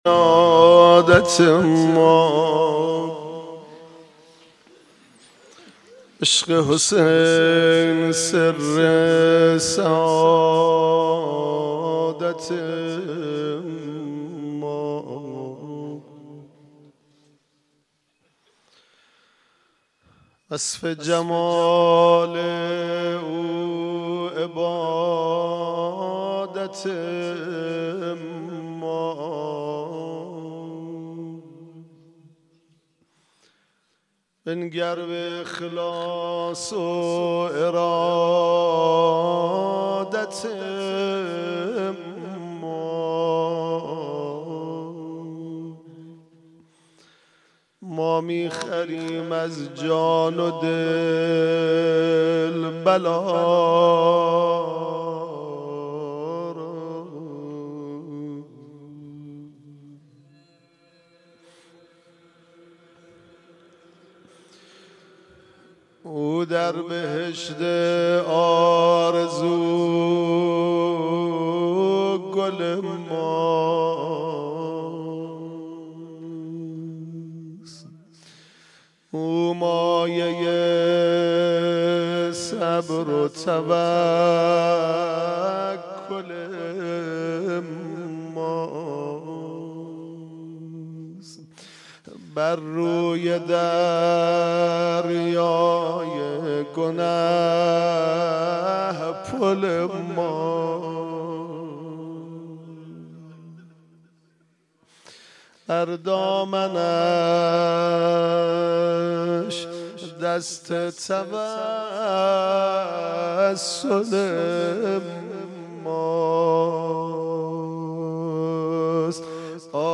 مداحی و روضه خوانی